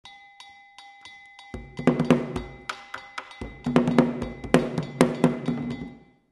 Kinka An Anlo-Ewe Secular Dance-Drumming
Gankogui (bitonal bell)
Atsimevu (lead drum)
Sogo (support drum)
Kidi (support drums)